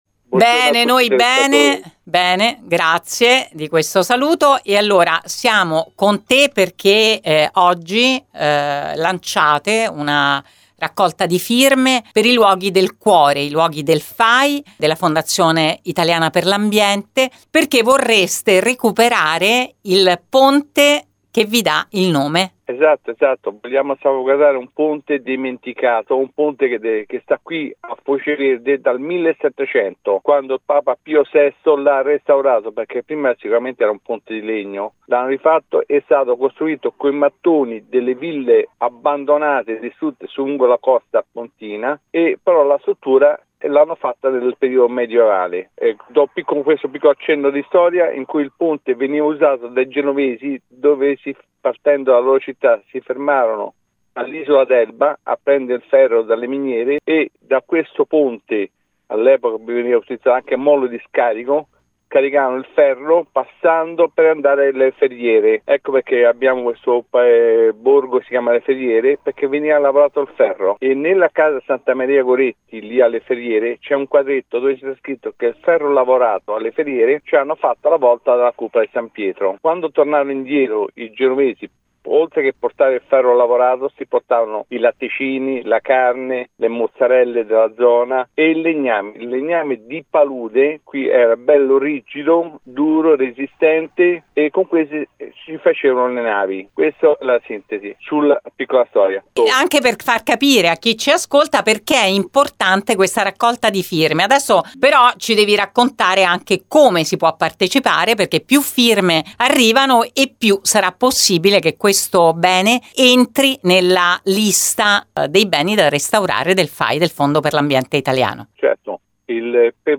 Ne abbiamo parlato con lui su Radio Immagine.